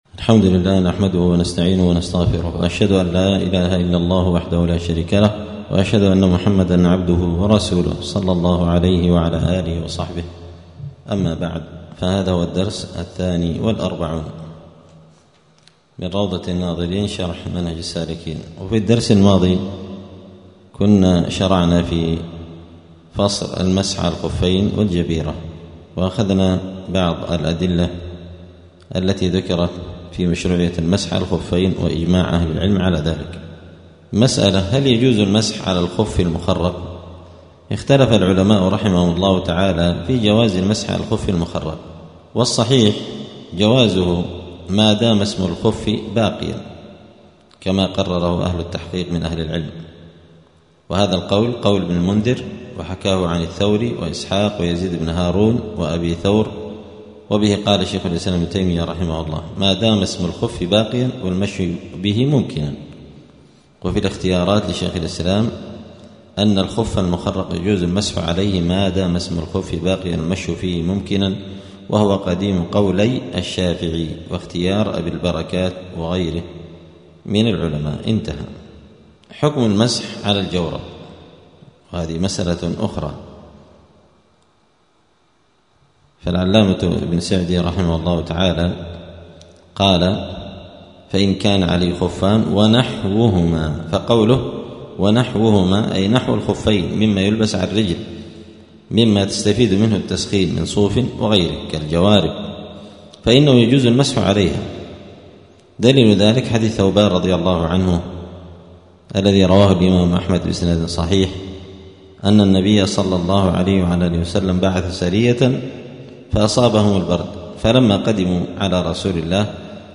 *الدرس الثاني والأربعون (42) {كتاب الطهارة باب صفة الوضوء هل يجوز المسح على الخف المخرق؟}*